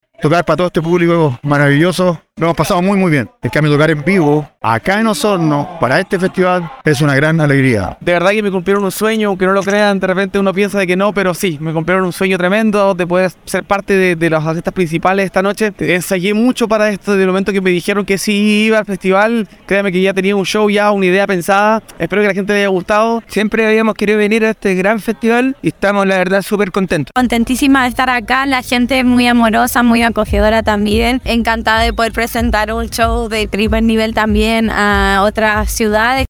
Dentro de las presentaciones principales se destacó el Grupo Los Tres, Villa Cariño, la cantante Denise Rosenthal y la agrupación de cumbia ranchera “Zúmbale Primo”, quienes dieron sus impresiones sobre el festival.